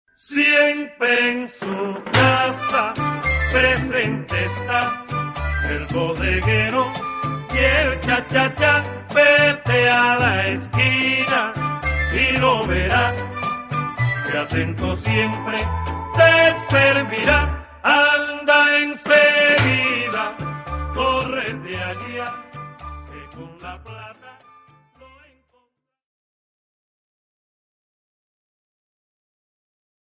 Obsazení: Flöte